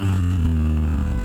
Play, download and share beck moan long original sound button!!!!
beck-moan-long-nicotine-and-gravy.mp3